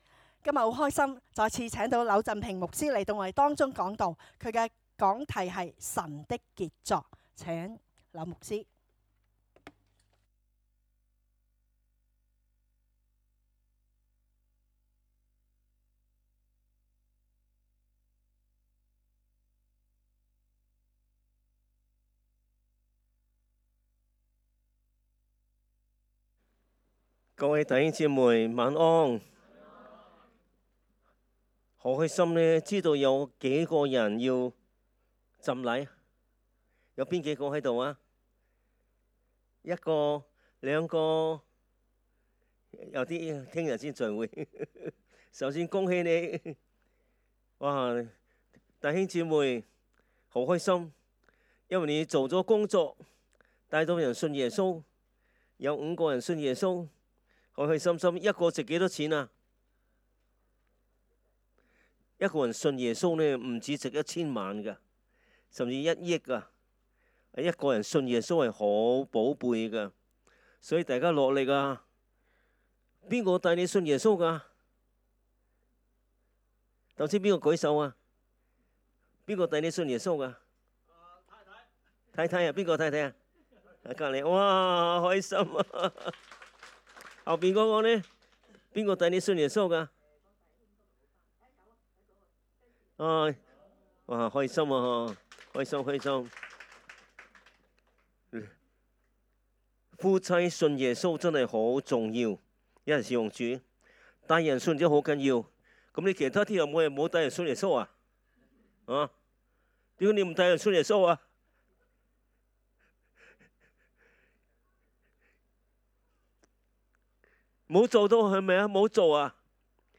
講道 ：神的傑作
崇拜講道